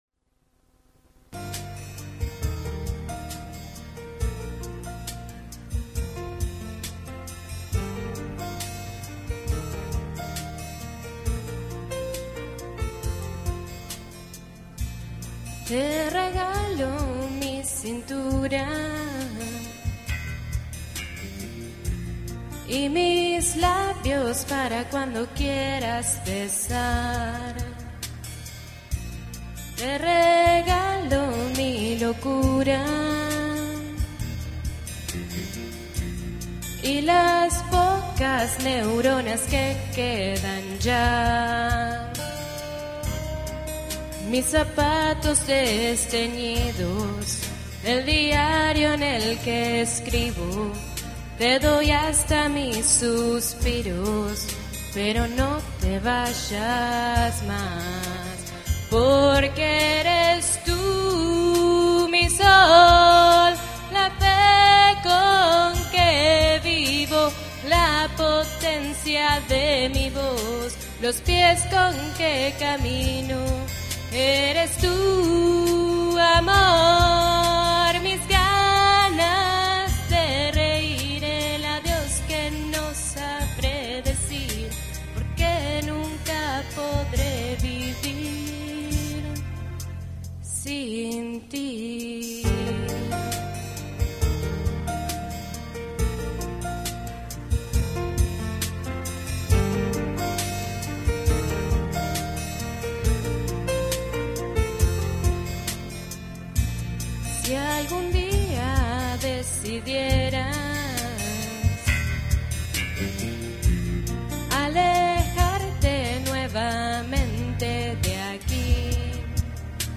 MUESTRAS :: Escuela de Música TEMPO
Canto